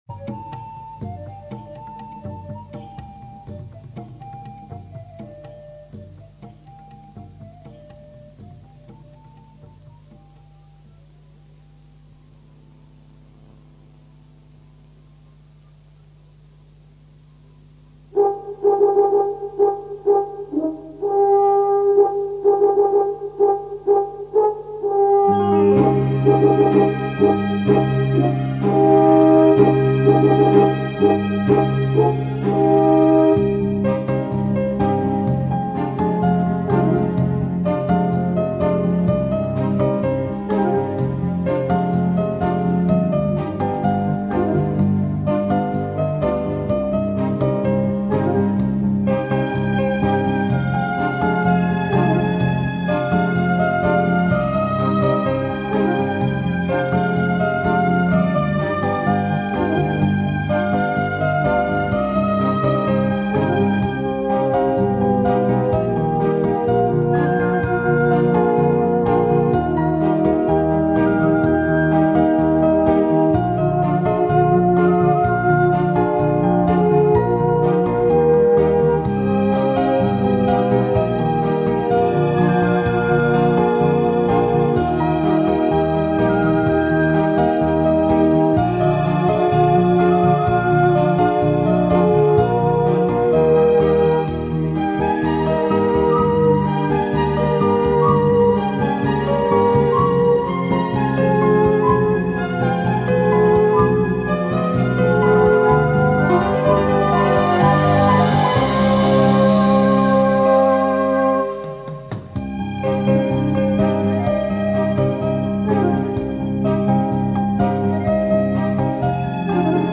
آهنگ بدون کلام